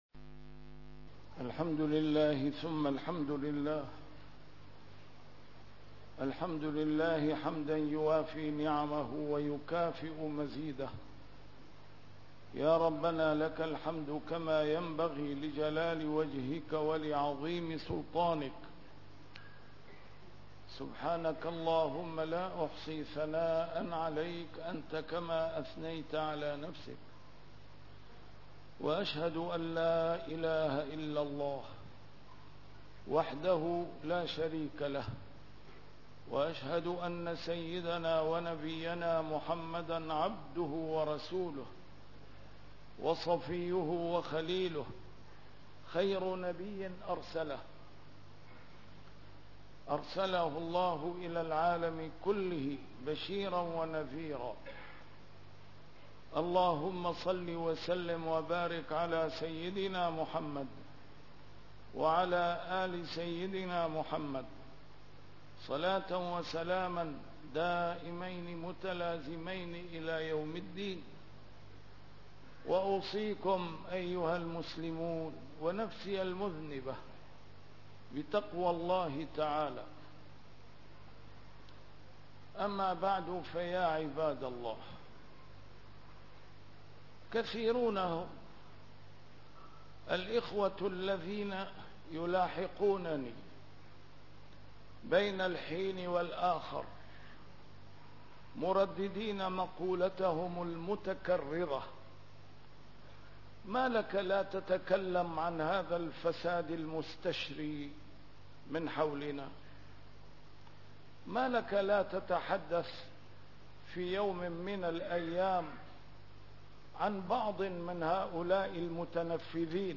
A MARTYR SCHOLAR: IMAM MUHAMMAD SAEED RAMADAN AL-BOUTI - الخطب - الراحمون يرحمهم الرحمن